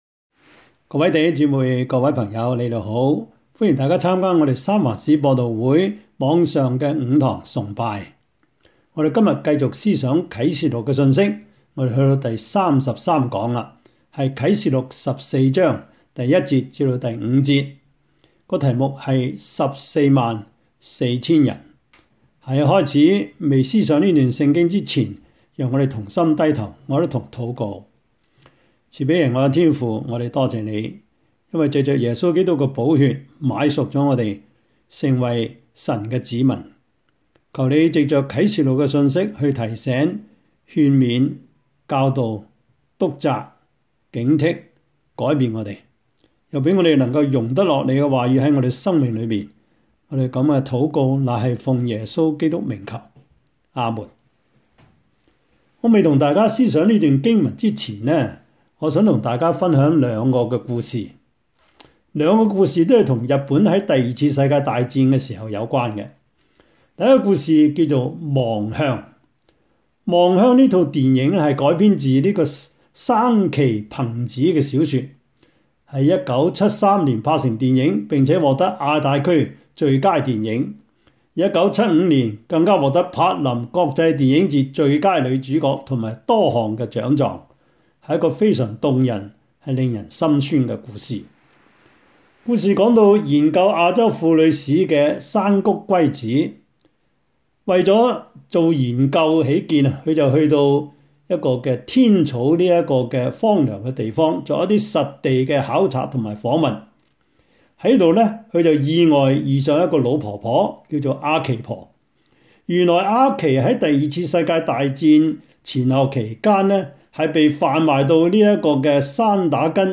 Service Type: 主日崇拜
Topics: 主日證道 « “開了吧!”